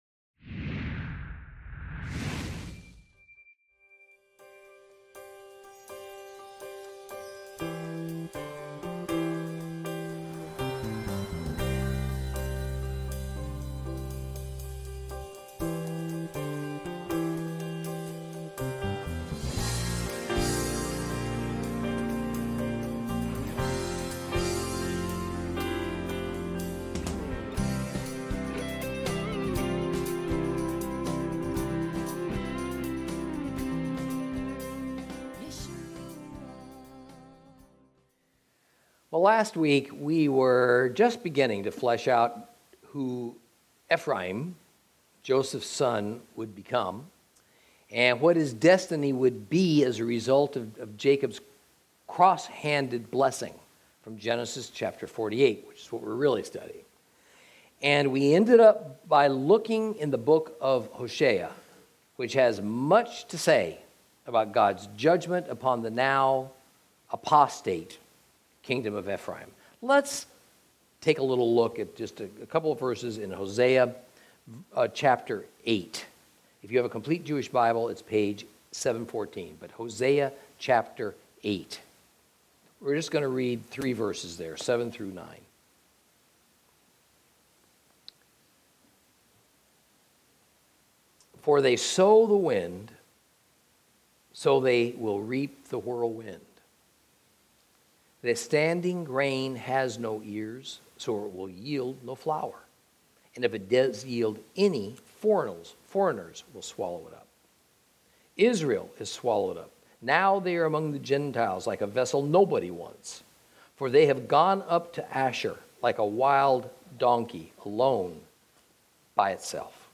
Lesson 42 Ch48 - Torah Class